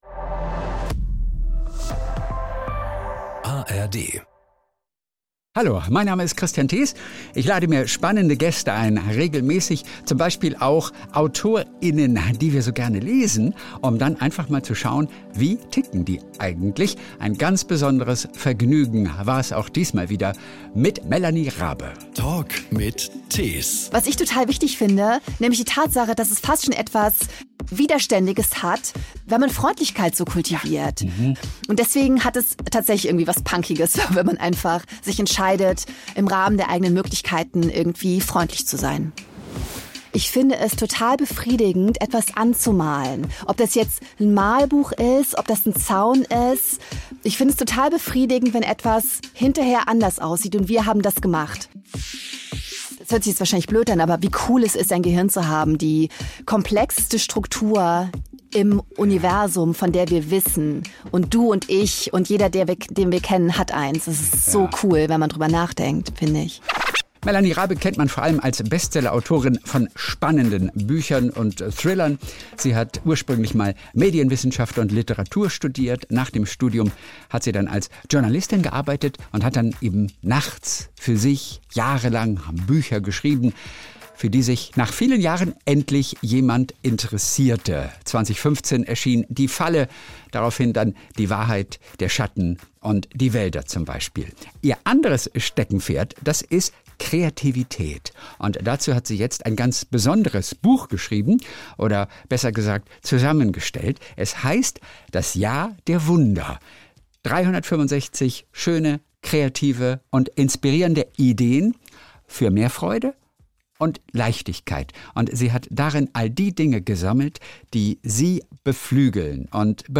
Jeden Sonntag unterhält er sich mit Stars, Promis und interessanten Menschen verschiedener Branchen.